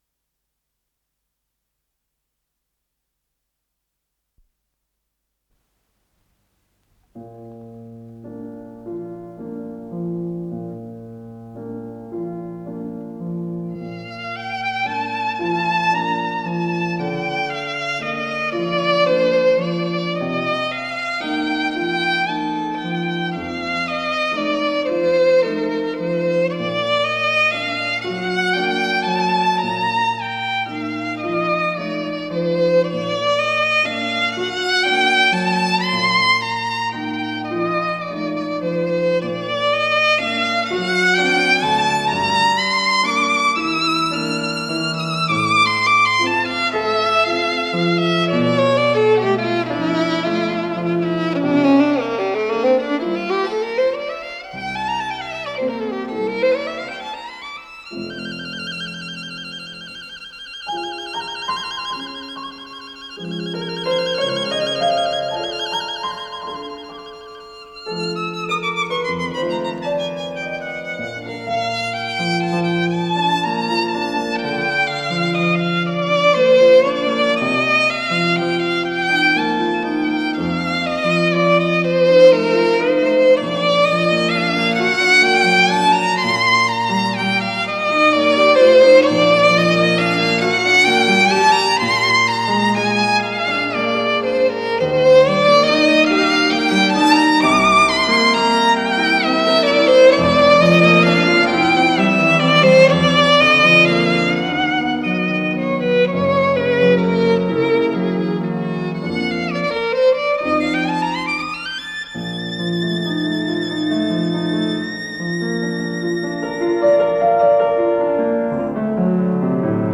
с профессиональной магнитной ленты
ВариантДубль моно